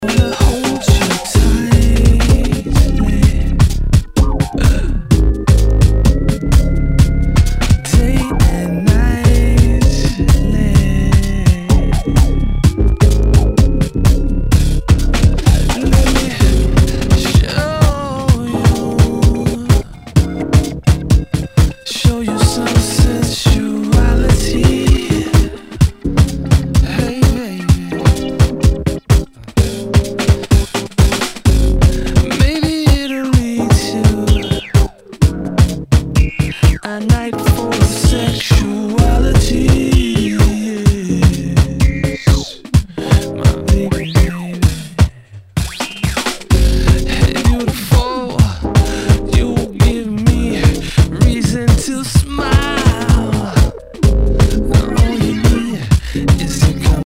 Nu- Jazz/BREAK BEATS